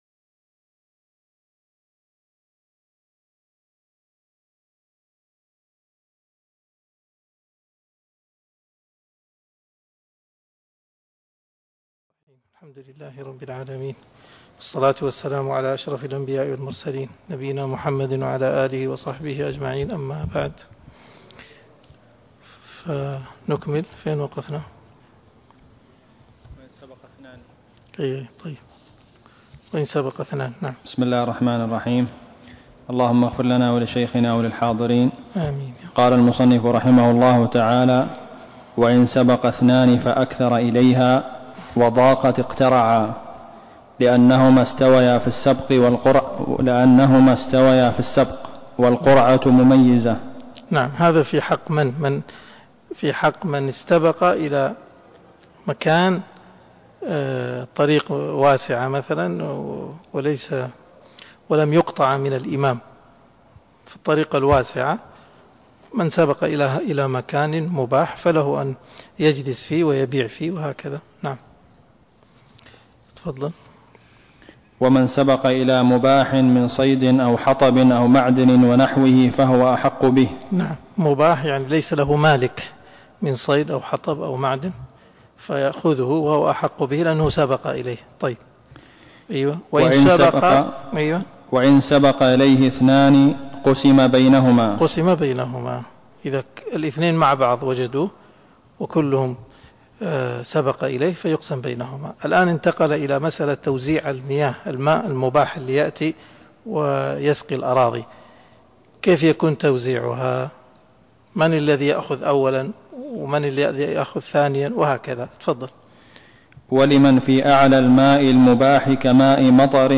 الدرس 108 (شرح الروض المربع)